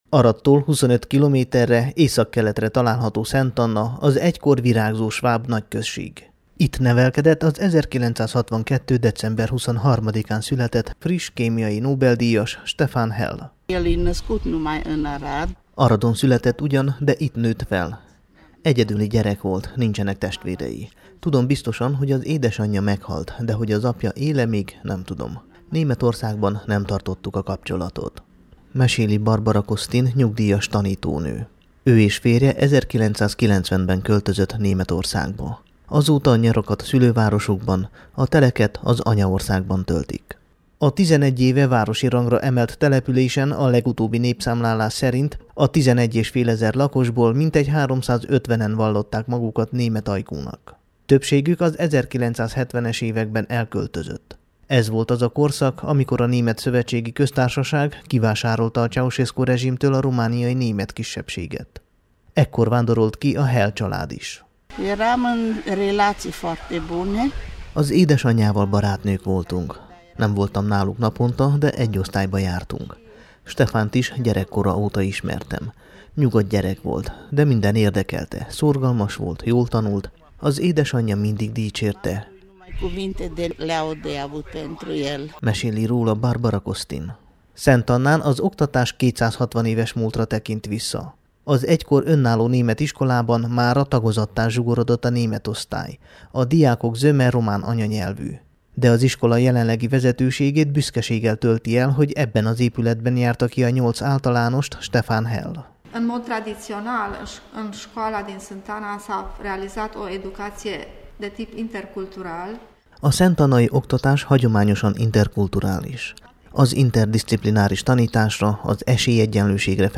és készített riportot a Temesvári Rádió számára.